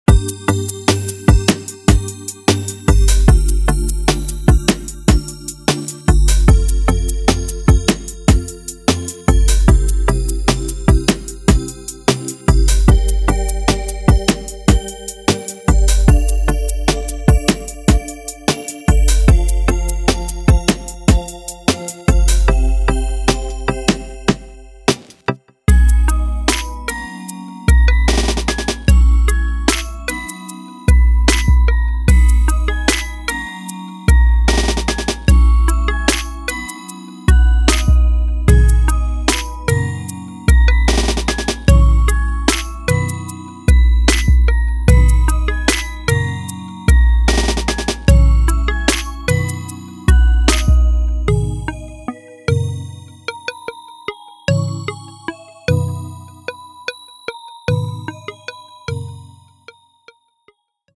Hip-Hop